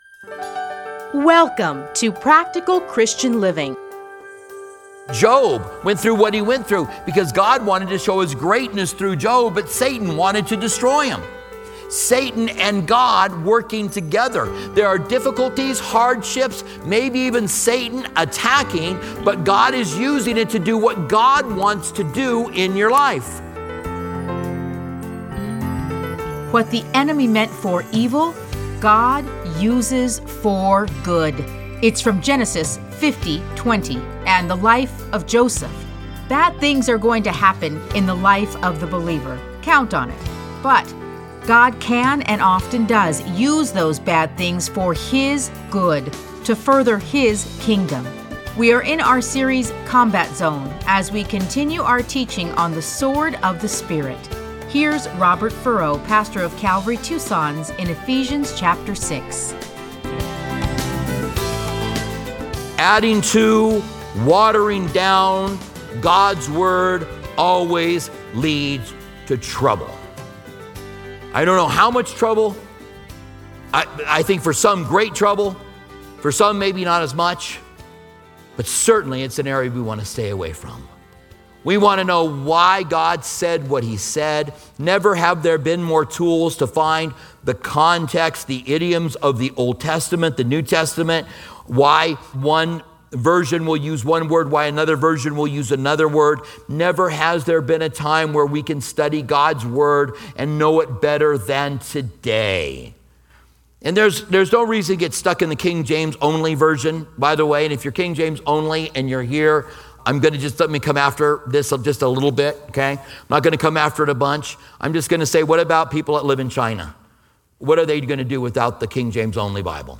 Listen to a teaching from Ephesians 6:17.